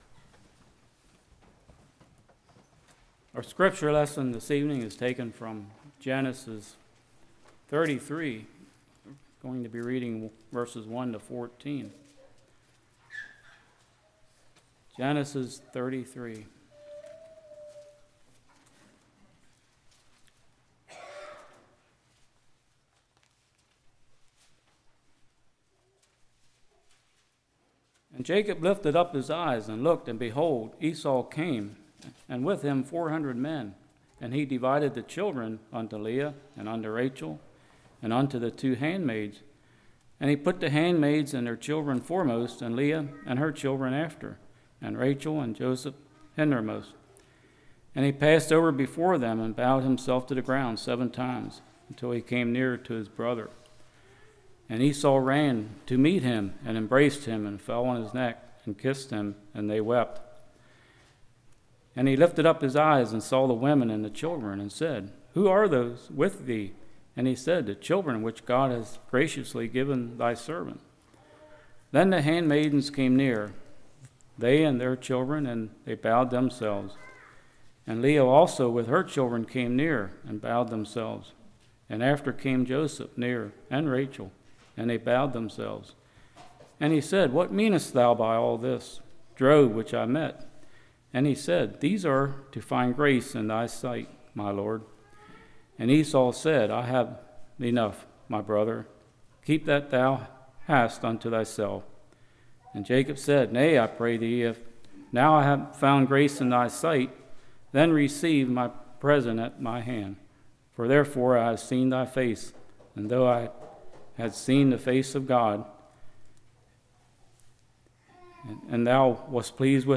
Passage: Genesis 33:1-14 Service Type: Evening